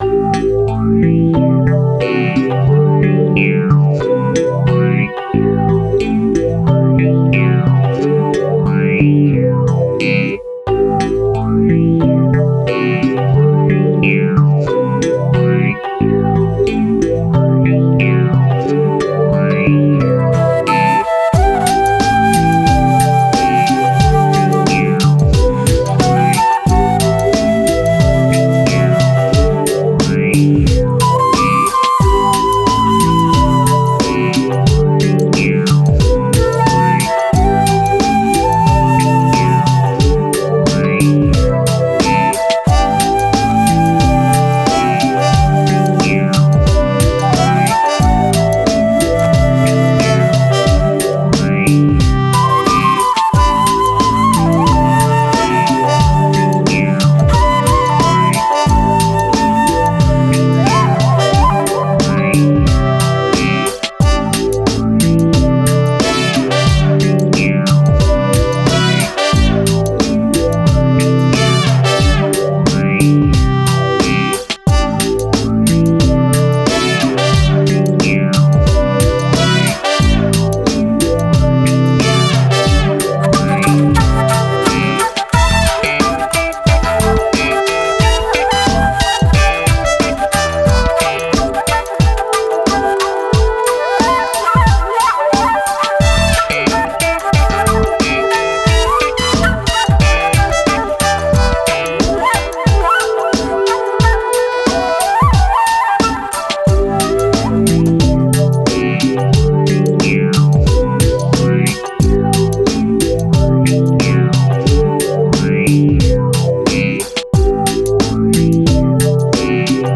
sax and flute
signature bass grooves